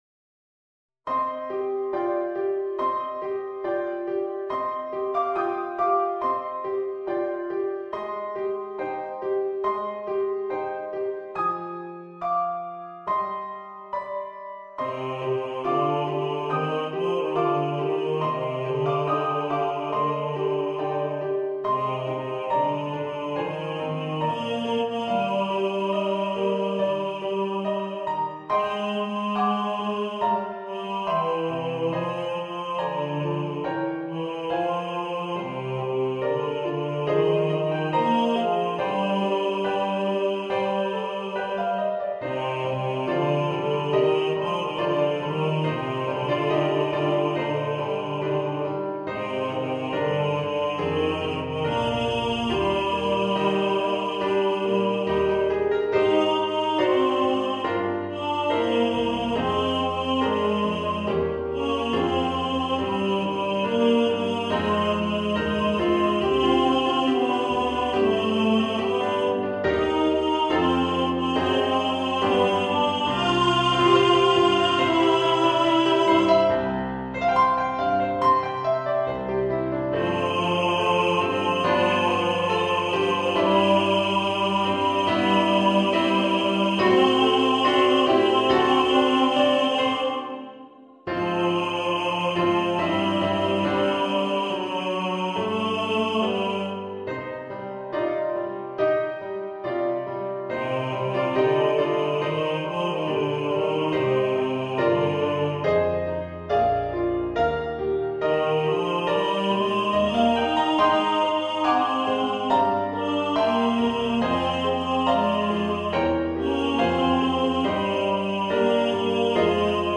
Christmas Children Tenor | Ipswich Hospital Community Choir
Christmas-Children-Tenor.mp3